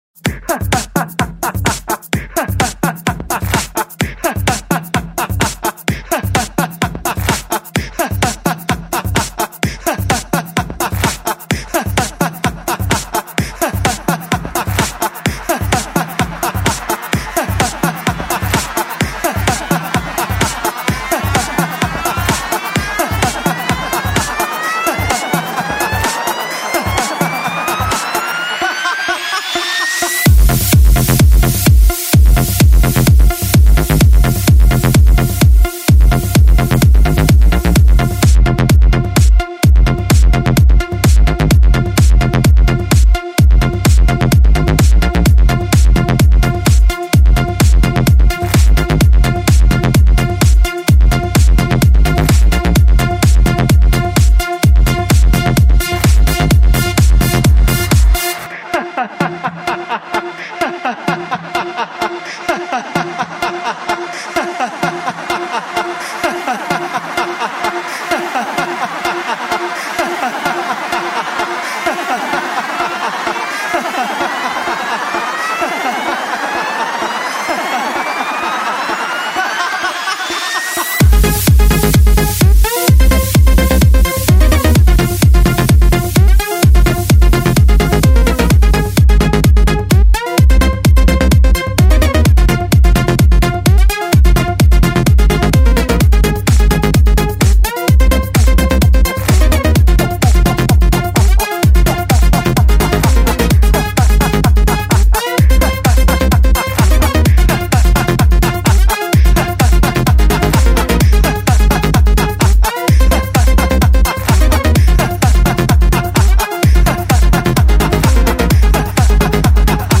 Замиксованный смех в клубном треке.